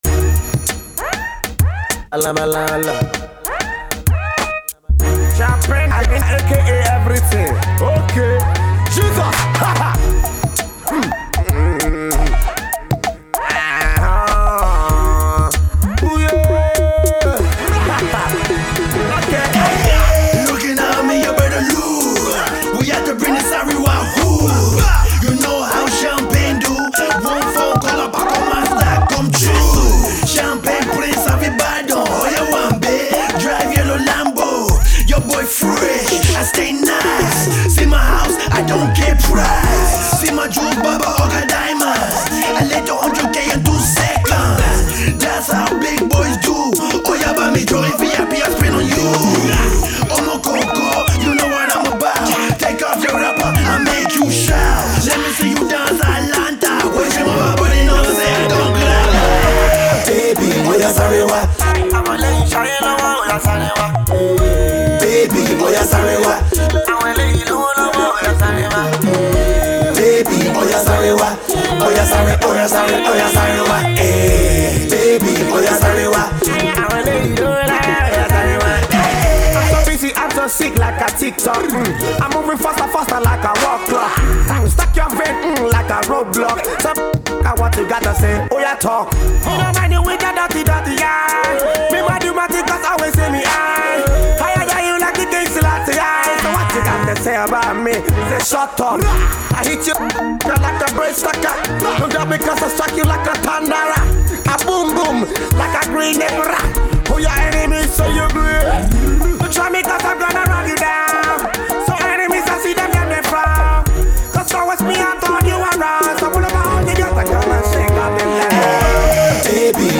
RADIO MIX